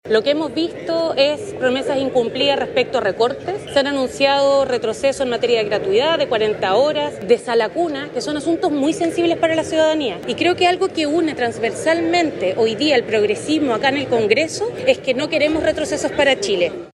A lo mismo apuntó la diputada Constanza Schonhaut (FA), quien sostuvo que abogarán porque no haya retrocesos.